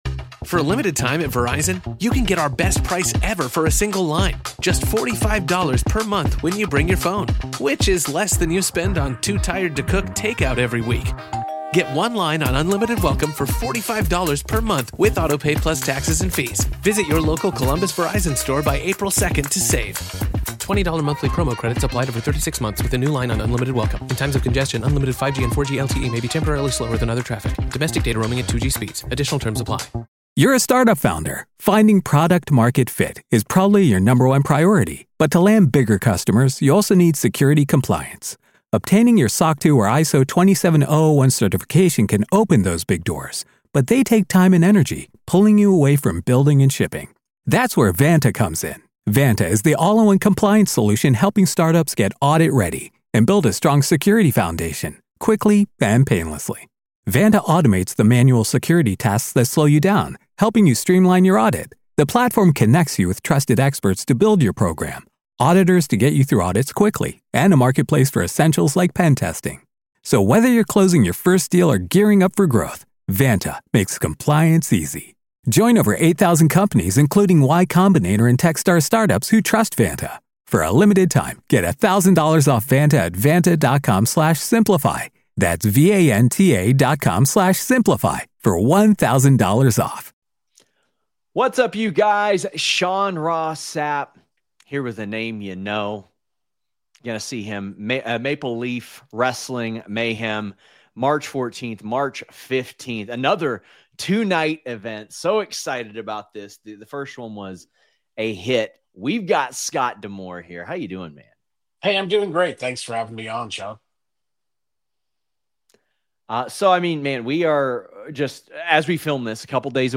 Shoot Interviews